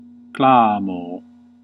Ääntäminen
IPA : /ˈkleɪm/ US : IPA : [ˈkleɪm]